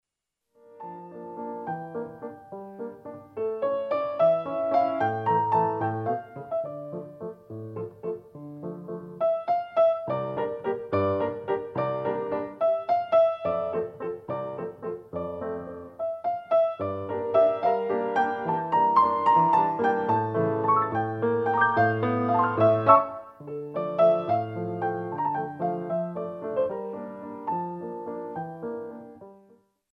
QUICK